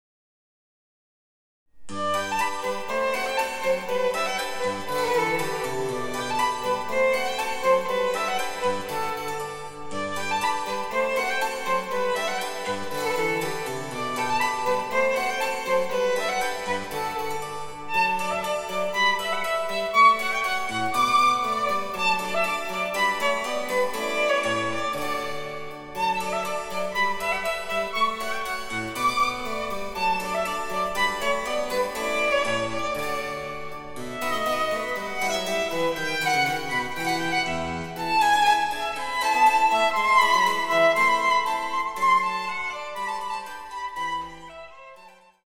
４つの楽章から成り、緩・急・緩・急の構成です。
第１楽章はラルゴ（広びろと）、8分の12拍子。
■ヴァイオリンによる演奏